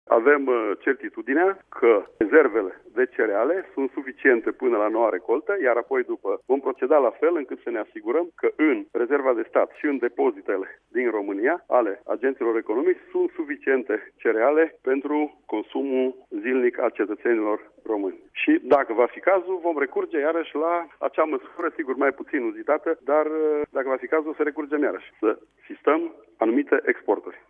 El a spus, la Radio România  Actualități că nu este exclusă sistarea vânzării peste graniţe a produselor de strictă necesitate, dacă va fi nevoie, dar că deocamdată exportul de cereale este sub control: